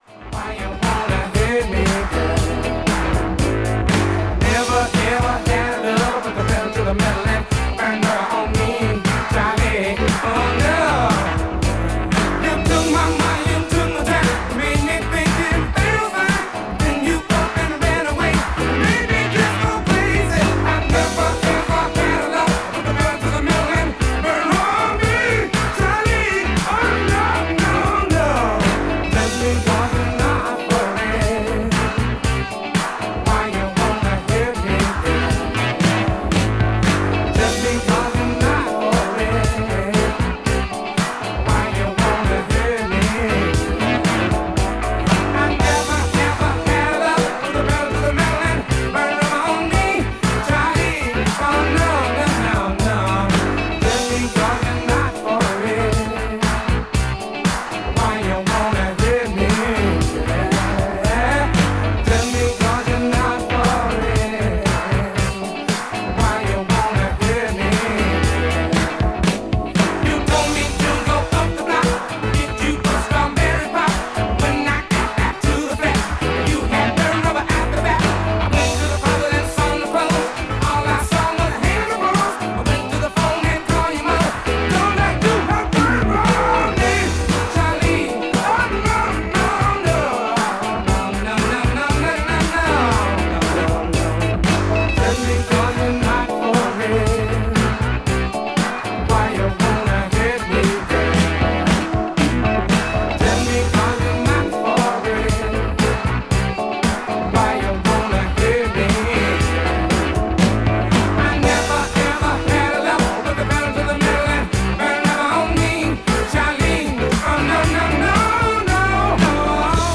STYLE Disco / Boogie